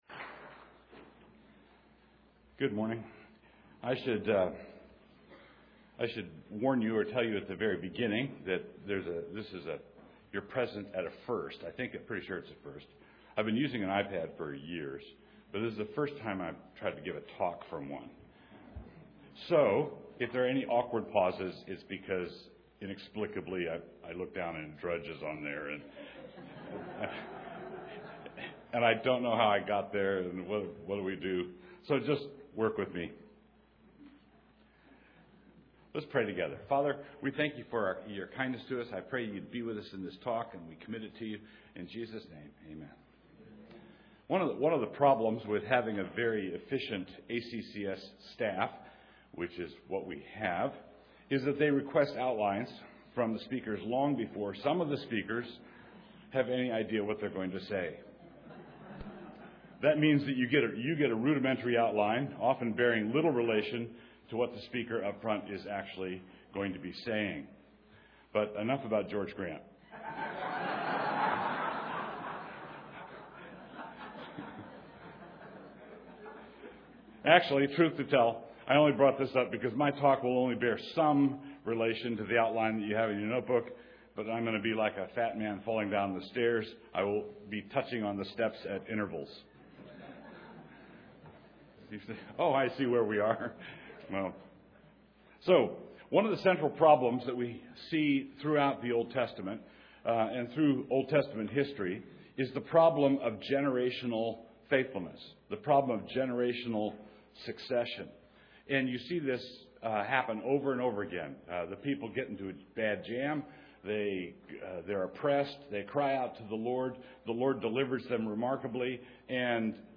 2014 Leaders Day Talk | 0:47:50 | Culture & Faith, Leadership & Strategic